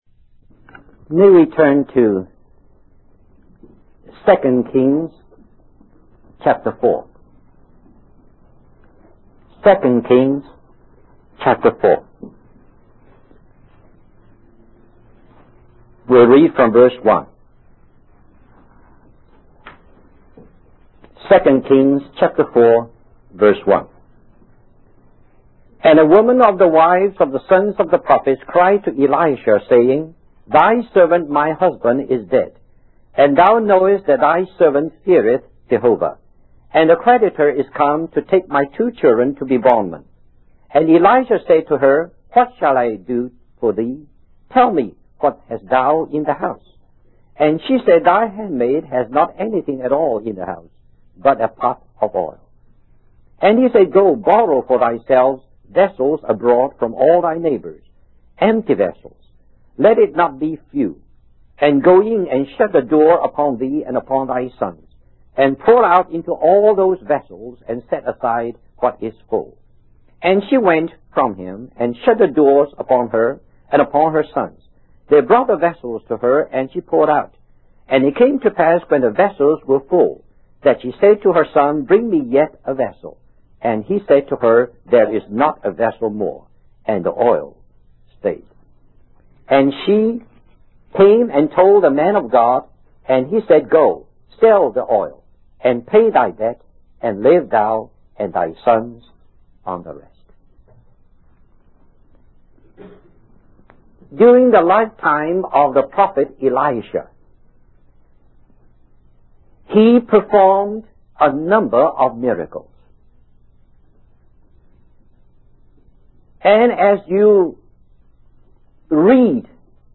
In this sermon, the preacher discusses the symbolism of the widow in the Bible and how it represents the church. He explains that the church is often oppressed and looked down upon by the world, just like a widow. The preacher also mentions the story of the widow who pleaded before an unrighteous judge, emphasizing the importance of persistent prayer.